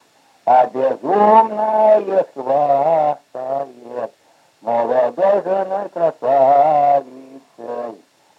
Отличные от нормативных окончания в именительном падеже единственного числа мужского рода прилагательных – безударная флексия –ый в соответствии с литературной -ой
/а-у-жл’е-у”-мно-йот хва”-стал да-ста-рой ма”-т’ер’-йу/